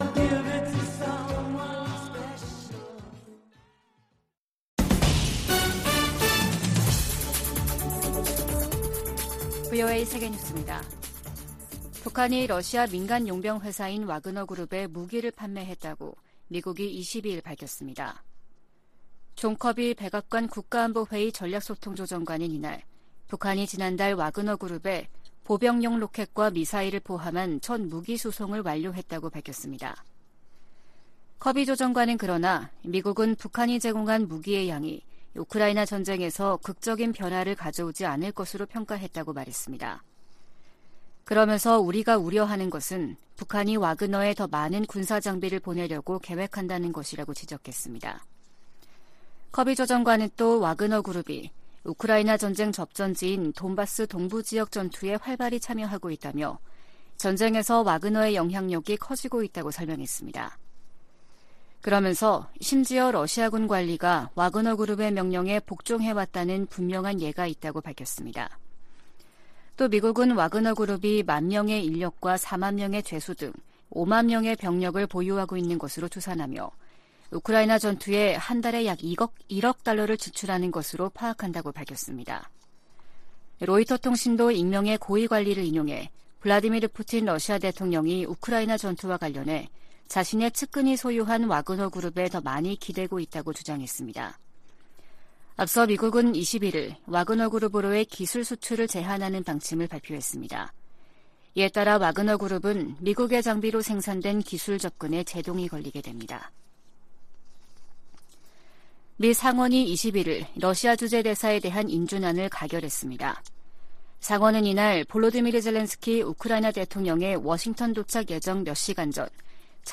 VOA 한국어 아침 뉴스 프로그램 '워싱턴 뉴스 광장' 2022년 12월 23일 방송입니다. 미국 의회가 2023회계연도 일괄 지출안에서 북한을 비롯한 적국들의 사이버 공격에 관한 조사 보고서를 제출할 것을 행정부에 요구했습니다. 한국 국가정보원은 북한이 내년엔 첨단기술과 안보 현안에 대한 탈취 목적의 사이버 첩보 활동을 더 활발하게 벌일 것으로 전망했습니다.